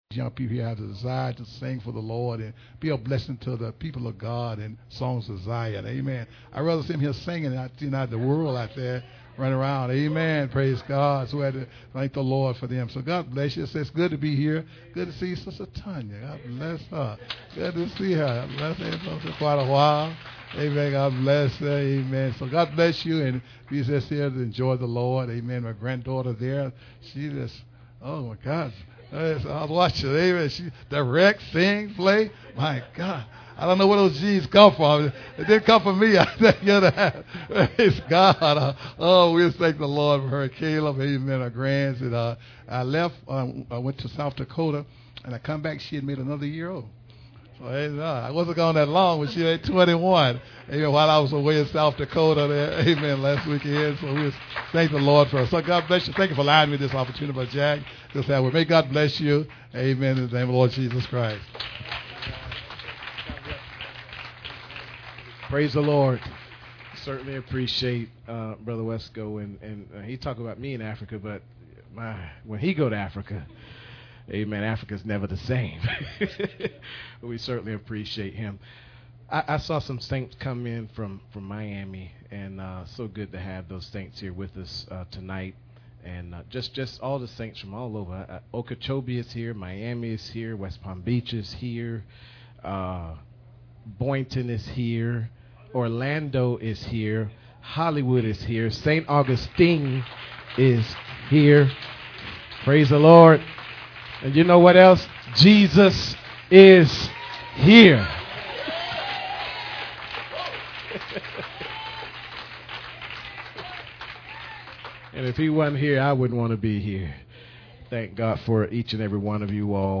SAT 10 Year Anniversary Concert